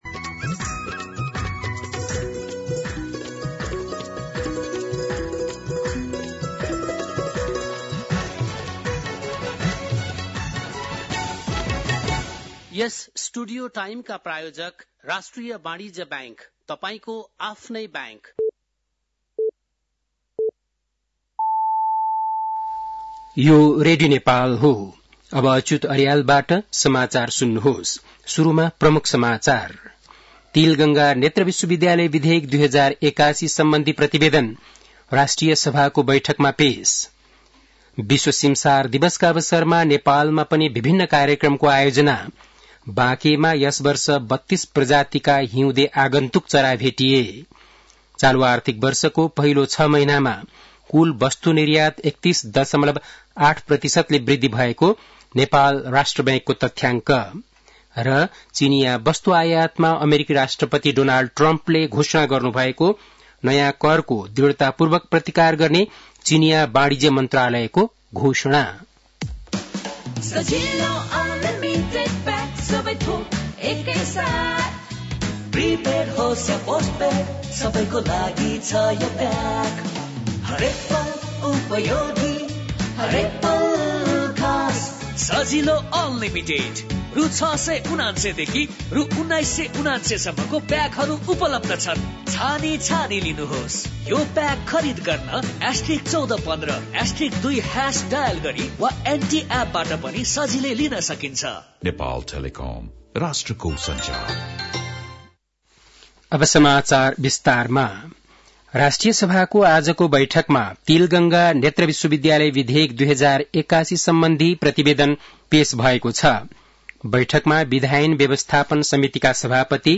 बेलुकी ७ बजेको नेपाली समाचार : २१ माघ , २०८१
7-PM-Nepali-News-10-20.mp3